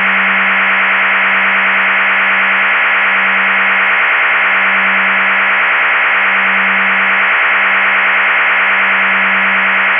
STANAG 4285 audio samples
STANAG4285_BRASS-idle.WAV